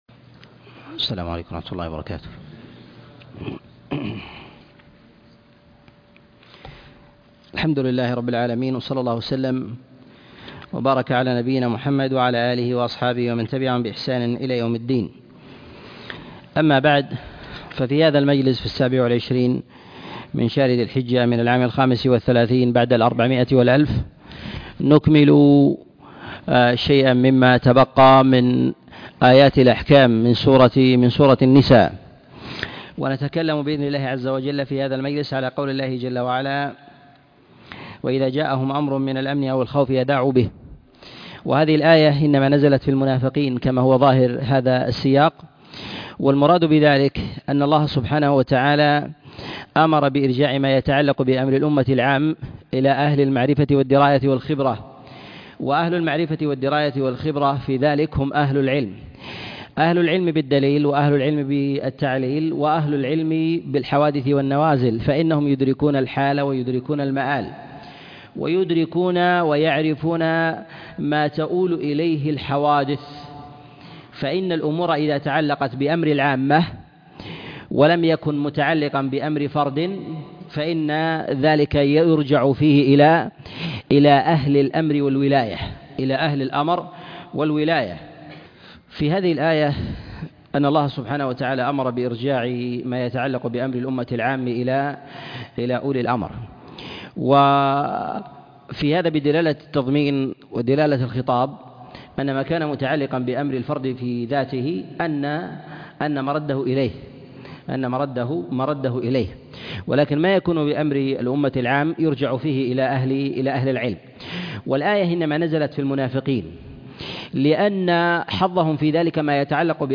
تفسير سورة النساء 19 - تفسير آيات الأحكام - الدرس الثالث والسبعون